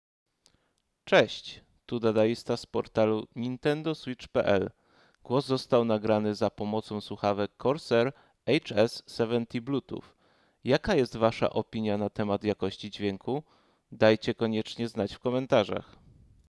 Przedstawiam wam próbkę głosu nagranego z Corsair HS70 Bluetooth, byście mogli sami ocenić, czy ta jakość jest dla was satysfakcjonująca.
Nagranie-Corsair-HS70BT.mp3